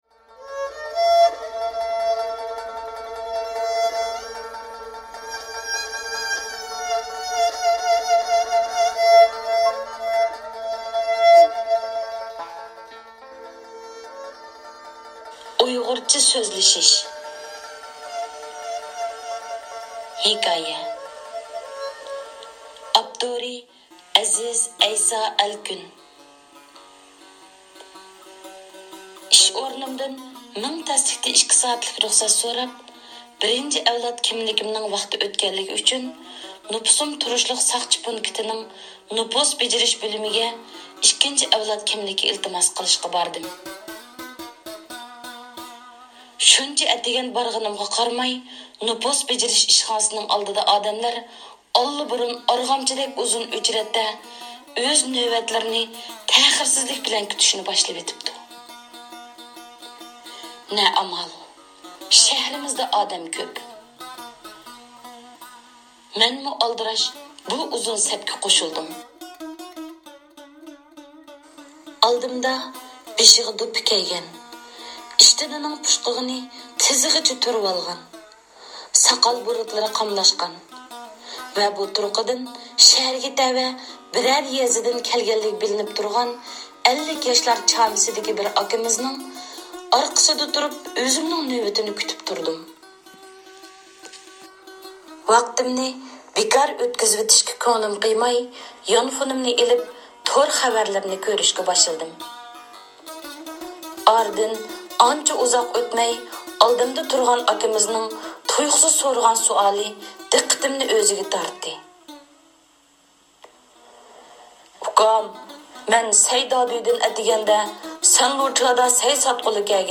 (ھېكايە)